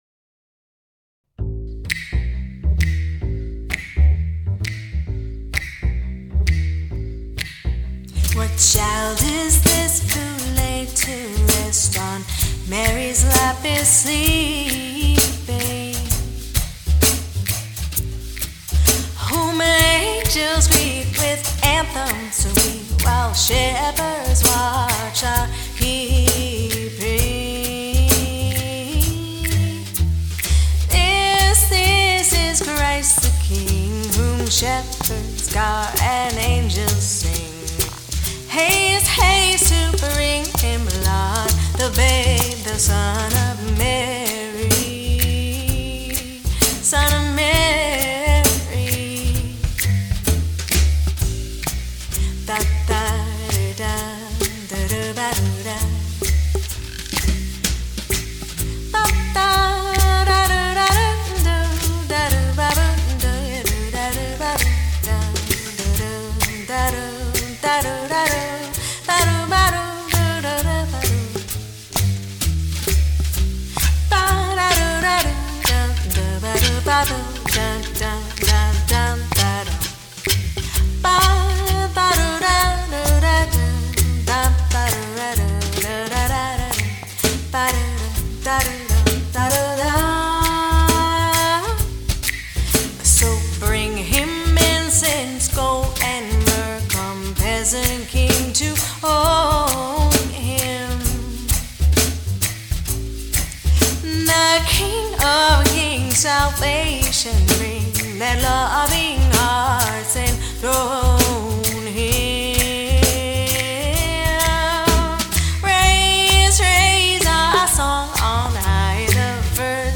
Christmas music to lift your spirit!